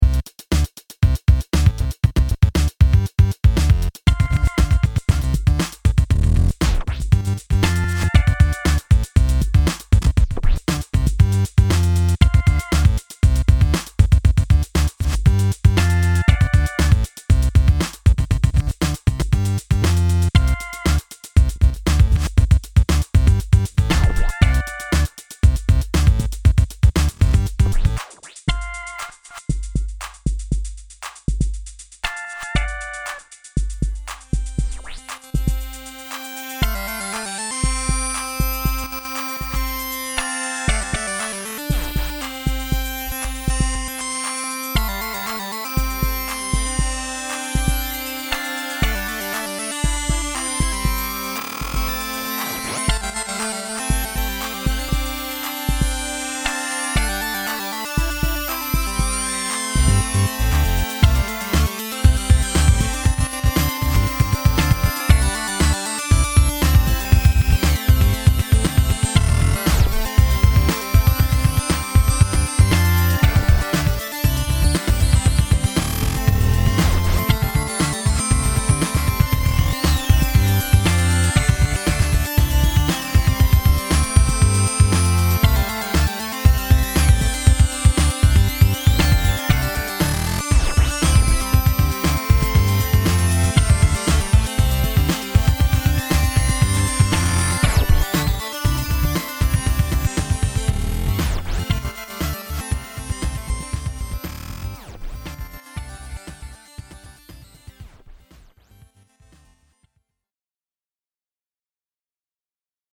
I made this song on Ableton.
I have become an abuser of compressors as you can probably tell.
Filed under: Instrumental | Comments (3)
I like the Twang, it's like a cowboy on his way to the disco.